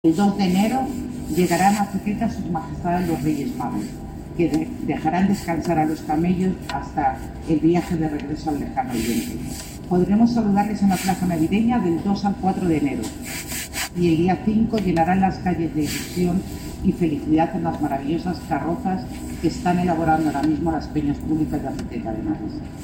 Declaraciones de la concejala de Fiestas sobre la visita de los Reyes Magos